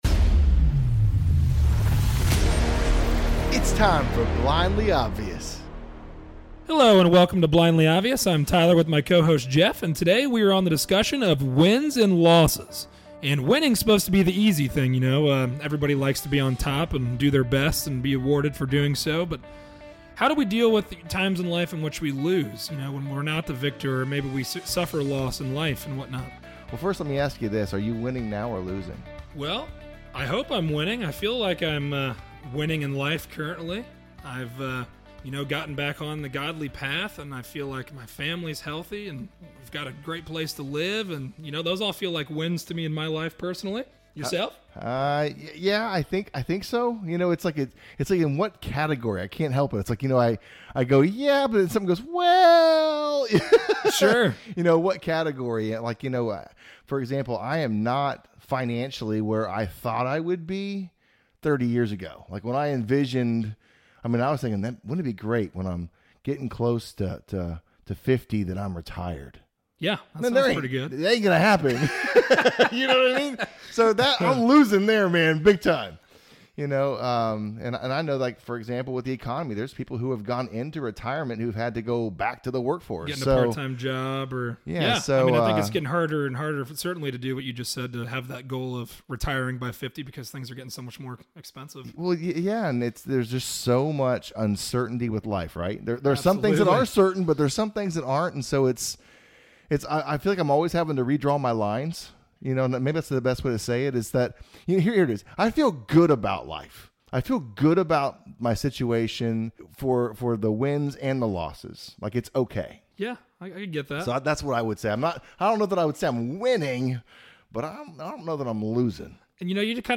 A conversation on dealing with life’s wins and losses. If we don’t have full control of the outcome is the difference in how we respond?